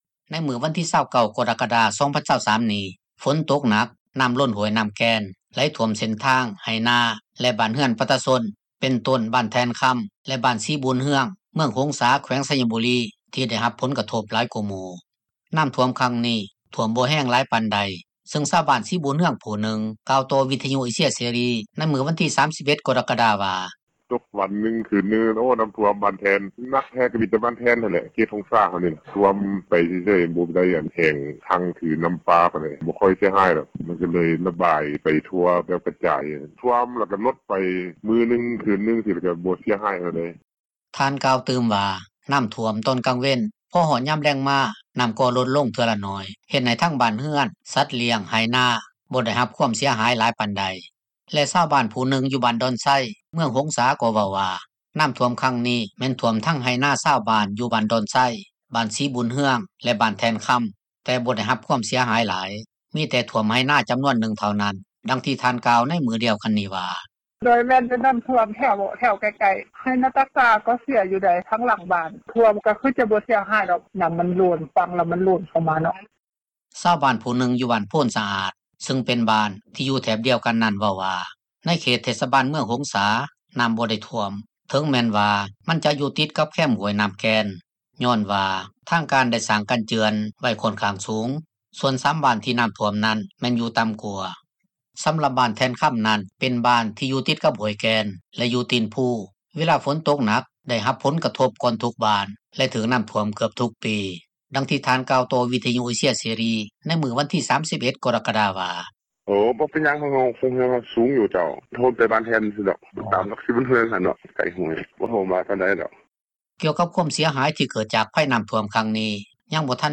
ນັກຂ່າວ ພົລເມືອງ
ດັ່ງຊາວບ້ານສີບຸນເຮືອງ ຜູ້ນຶ່ງກ່າວຕໍ່ວິທຍຸ ເອເຊັຽ ເສຣີ ໃນມື້ວັນທີ 31 ກໍຣະກະດາວ່າ:
ດັ່ງເຈົ້າໜ້າທີ່ຫ້ອງການປົກຄອງເມືອງ ຫົງສາ ກ່າວຕໍ່ວິທຍຸ ເອເຊັຽ ເສຣີ ໃນມື້ດຽວກັນນີ້ວ່າ: